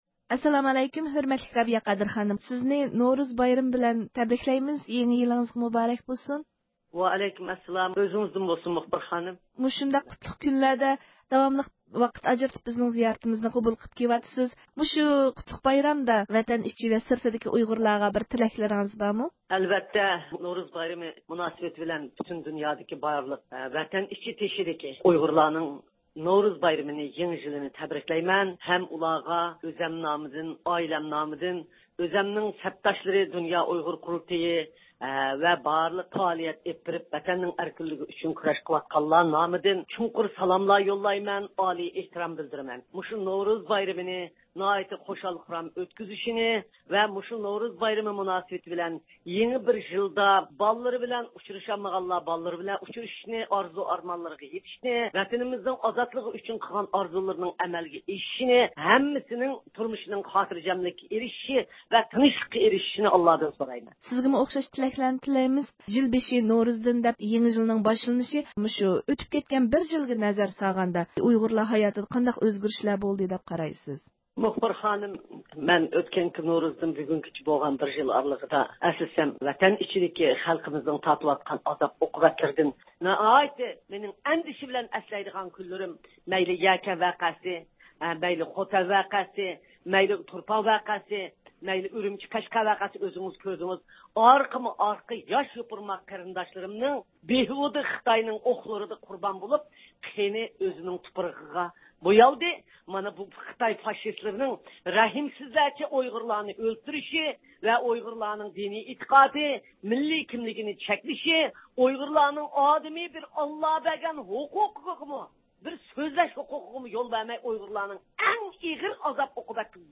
بۈگۈن نورۇز بايرىمى مۇناسىۋىتى بىلەن زىيارىتىمىزنى قوبۇل قىلغان رابىيە خانىم بارلىق ئۇيغۇرلارنىڭ نورۇز بايرىمىنى تەبرىكلىدى.